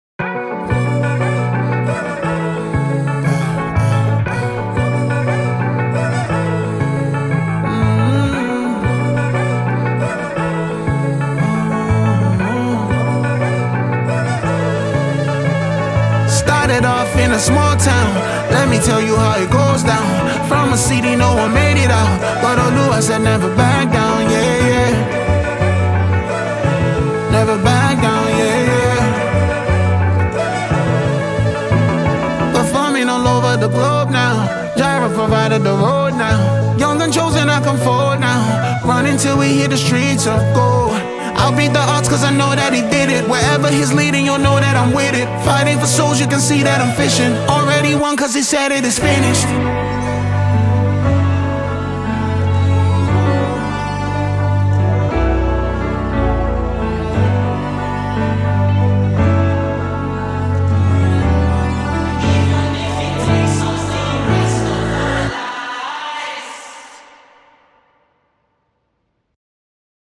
Mp3 Gospel Songs
the Nigerian Afro gospel singer